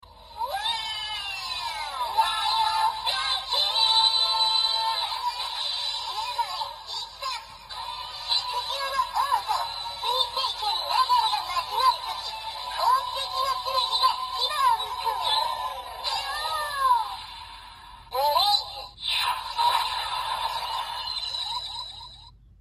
剑锋饱藏音效.MP3